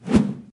throwable_woosh_high_00.ogg